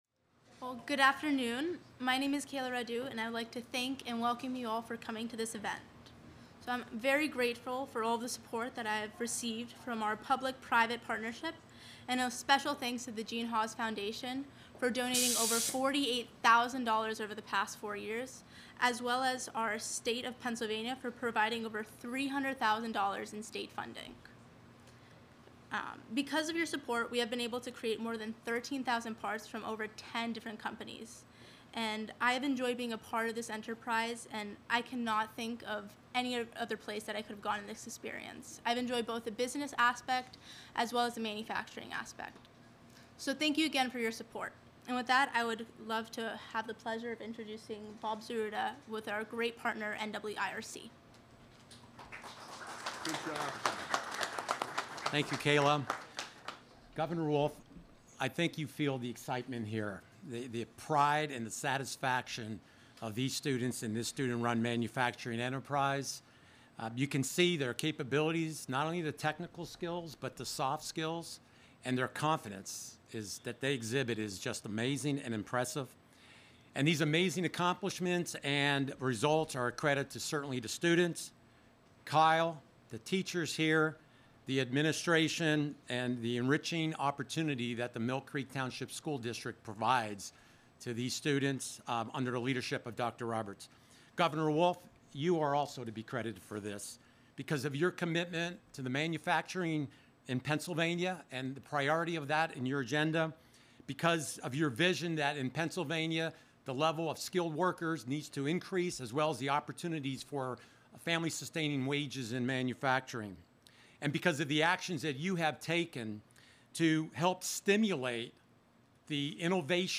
Governor Wolf Highlights Long-Standing Support for Manufacturing Industry of the Future at McDowell Manufacturing Tour